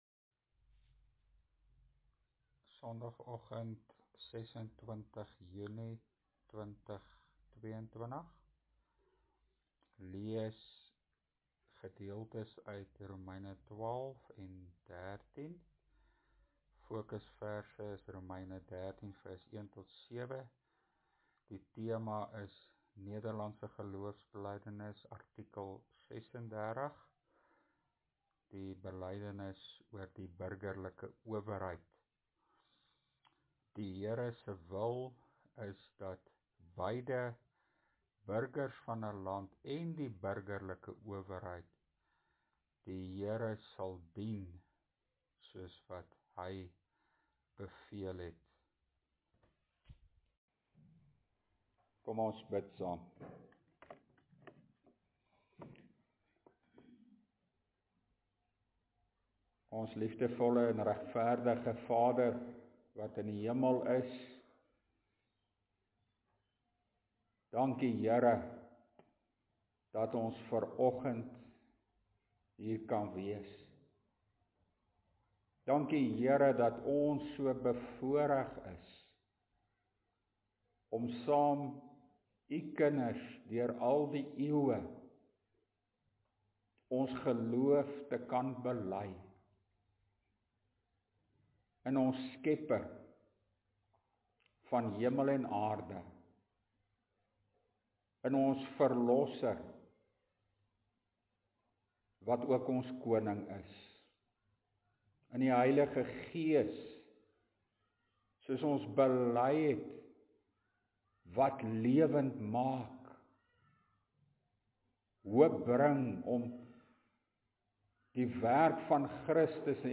LEER PREDIKING: NGB artikel 36 – Die burgerlike owerheid (Romeine 13:1-7)